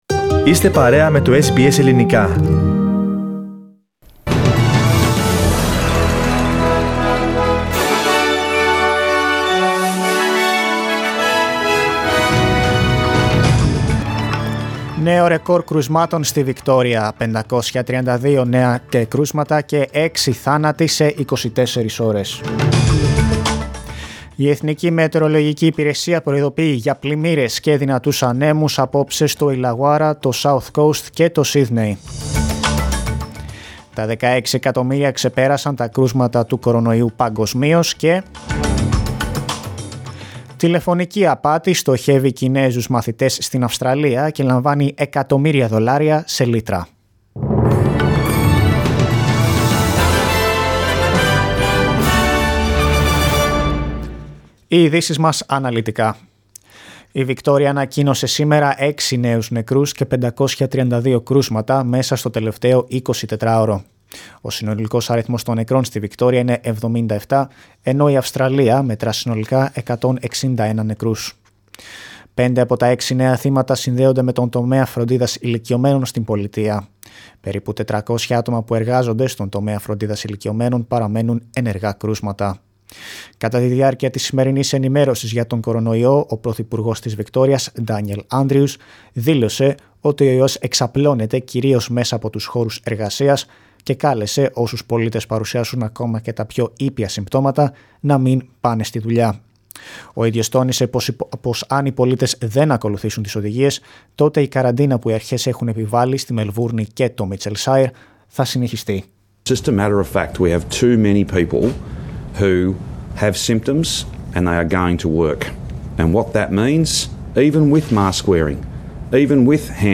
News from Australia, Greece, Cyprus and the world, in the news bulletin of Monday 27th of July.